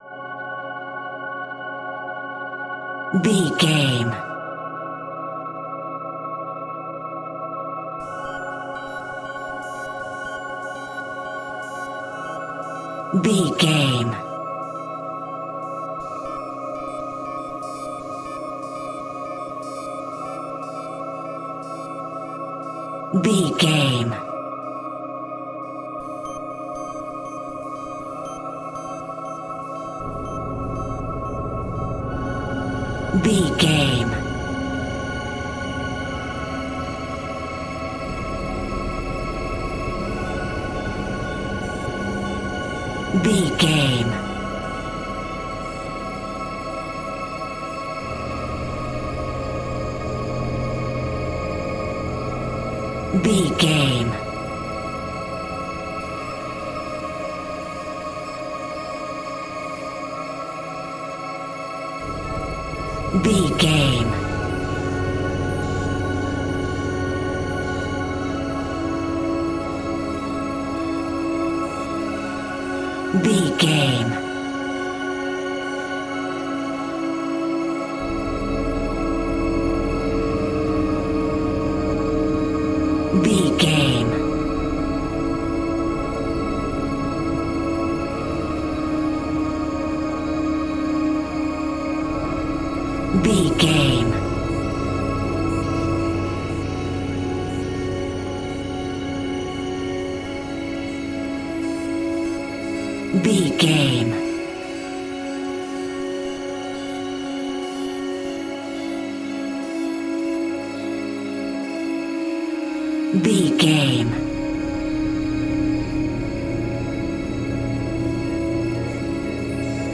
Thriller
Aeolian/Minor
F#
Slow
scary
tension
ominous
dark
haunting
eerie
synthesiser
strings
ambience
pads